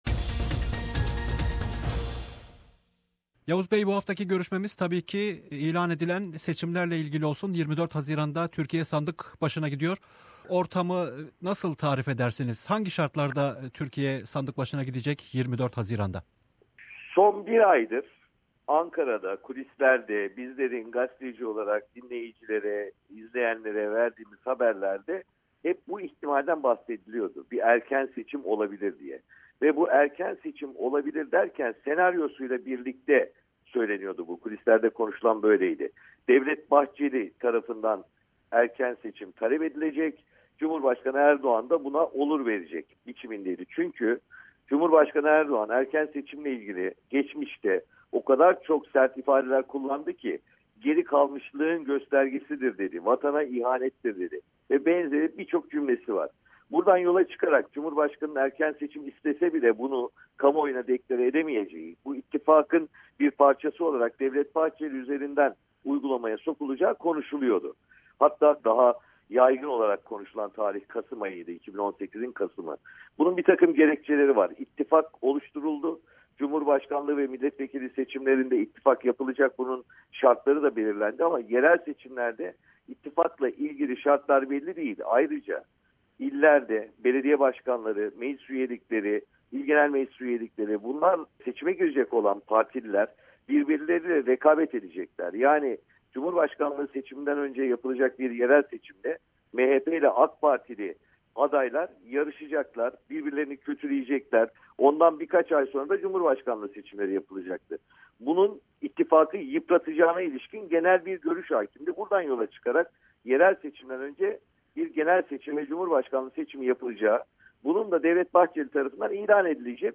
Gazeteci Yavuz Oğhan, Türkiye'nin erken seçim gündemini değerlendiriyor.